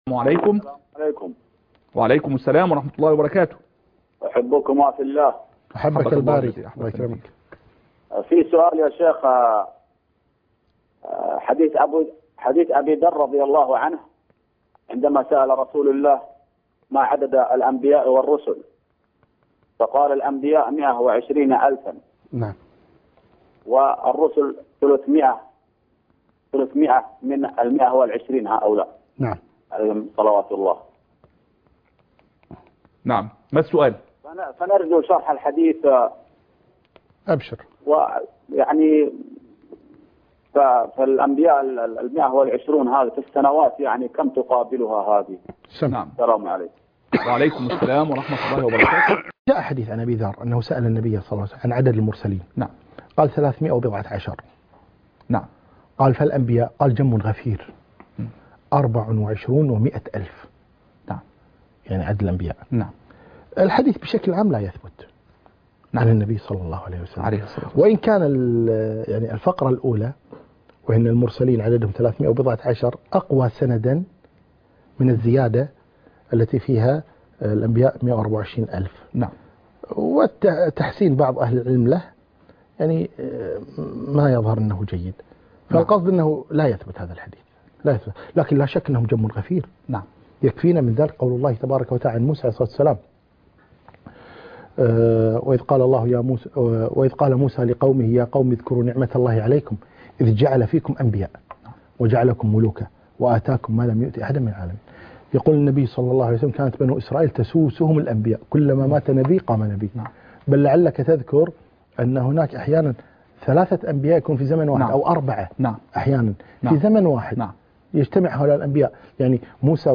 الحديث وعلومه     شرح الأحاديث وبيان فقهها